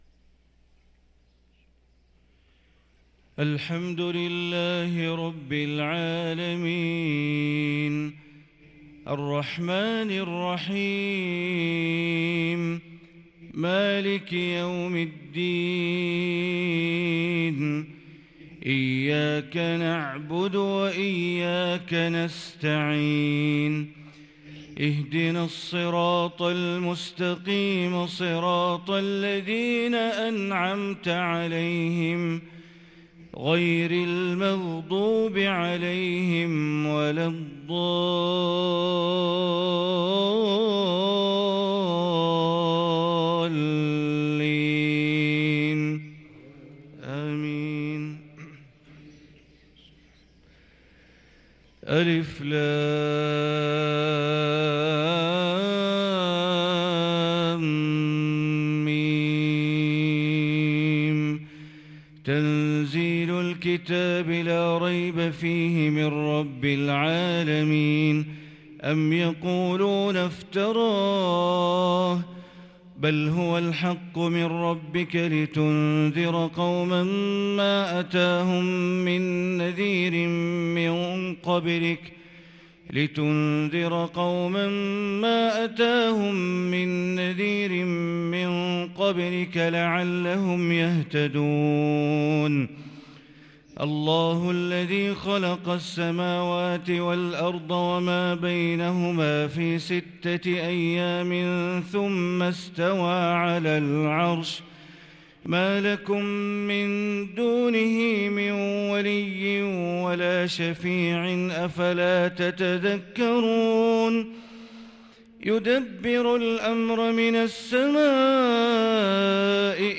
صلاة الفجر للقارئ بندر بليلة 13 جمادي الآخر 1444 هـ